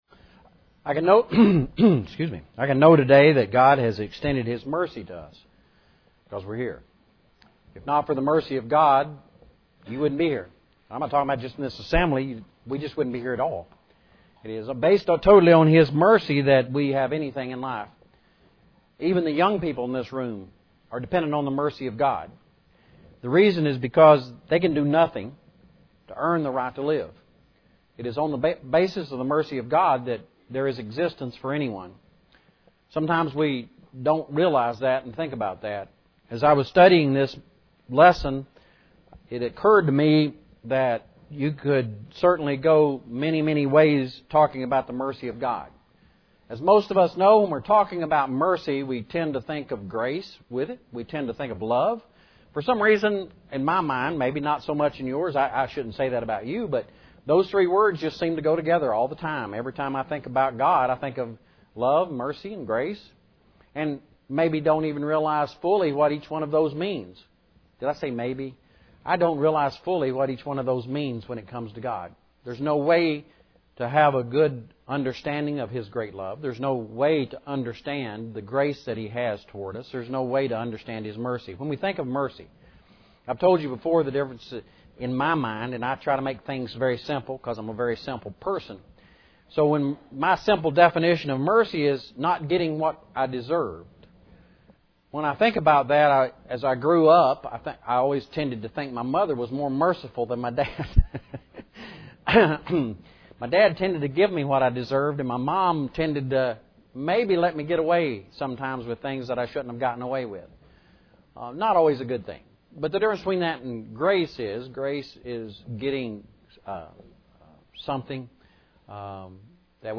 Today was the first “5th Sunday” of 2017 and it is the tradition at Waynesville is to do a mix of songs, scripture, and a short lesson on a single theme, followed by a second lesson on the same theme for any month with 5 Sundays. Today’s theme was “Mercy”.